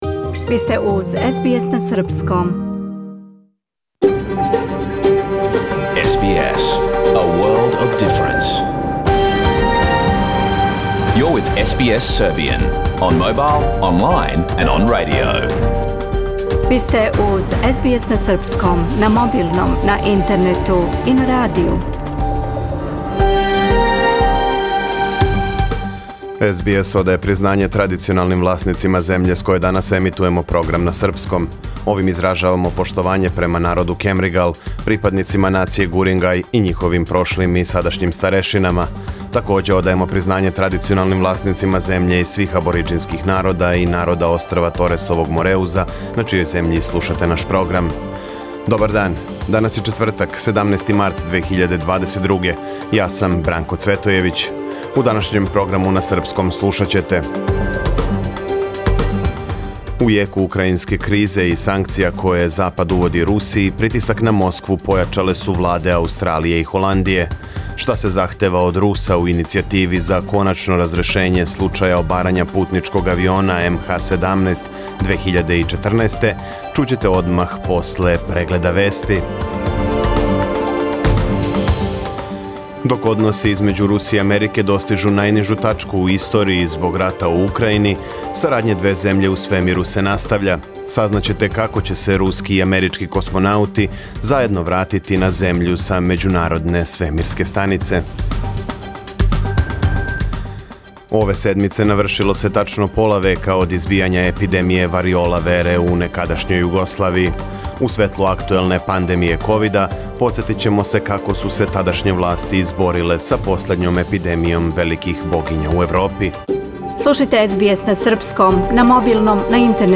Програм емитован уживо 17. марта 2022. године
Ако сте пропустили нашу емисију, сада можете да је слушате у целини као подкаст, без реклама.